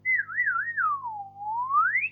Cartoon spin
Category 🤣 Funny
cartoon comedy dizzy funny silly spin spinning whistle sound effect free sound royalty free Funny